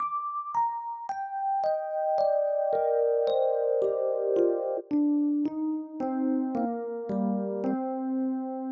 30 ElPiano PT3.wav